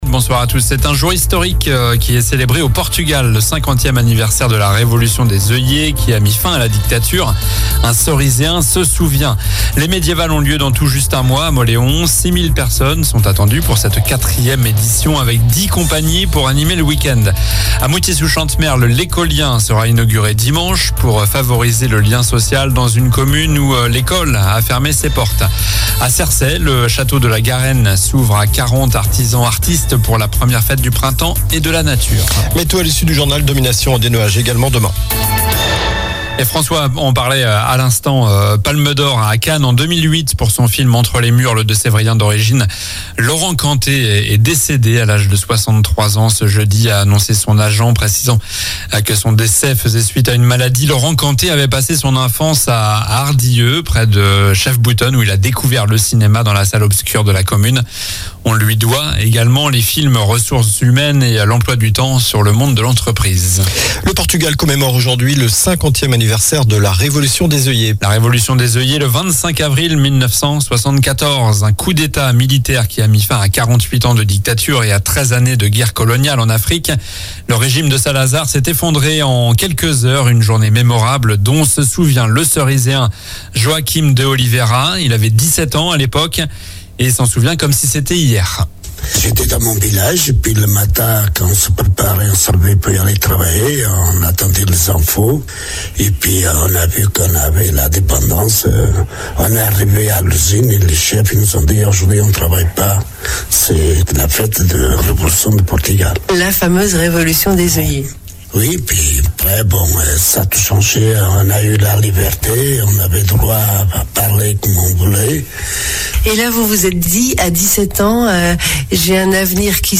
COLLINES LA RADIO : Réécoutez les flash infos et les différentes chroniques de votre radio⬦
L'info près de chez vous